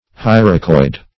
Search Result for " hyracoid" : The Collaborative International Dictionary of English v.0.48: Hyracoid \Hy"ra*coid\, a. (Zool.)